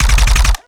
GUNAuto_RPU1 B Burst_05_SFRMS_SCIWPNS.wav